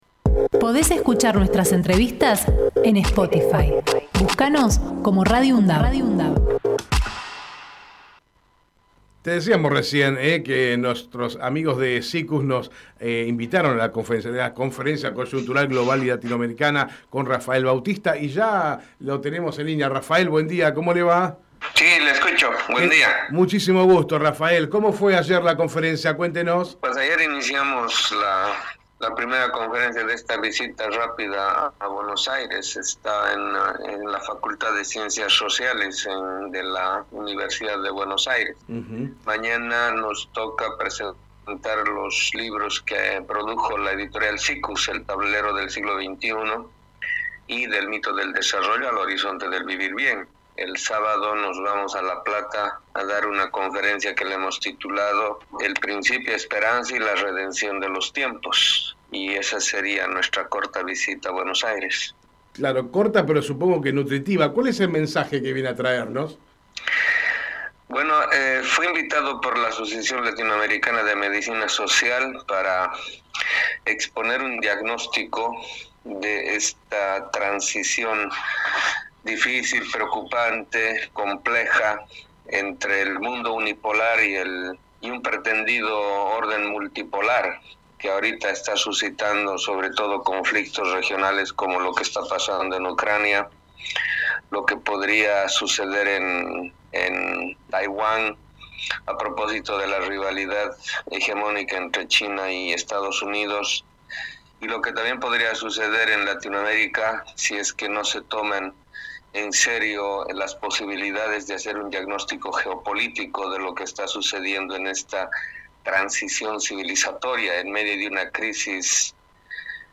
Compartimos la entrevista realizada en Hacemos PyE